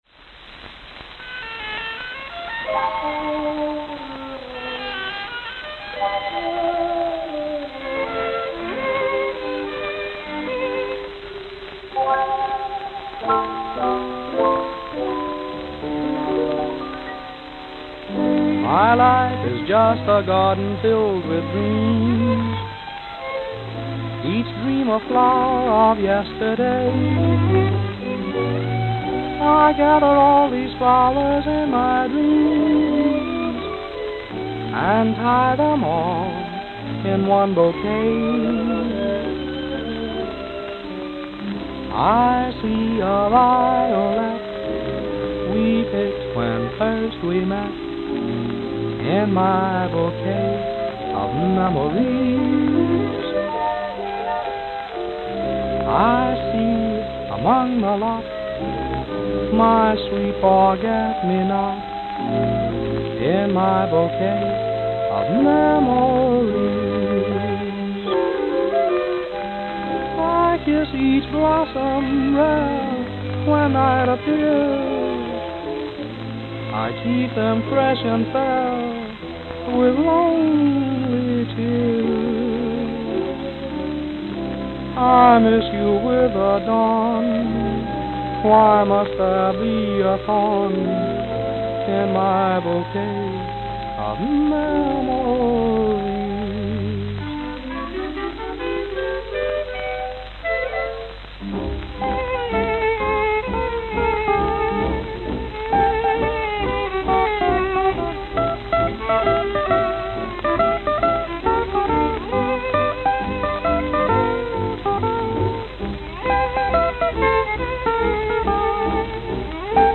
Note: Worn. Note: Worn.